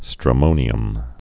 (strə-mōnē-əm)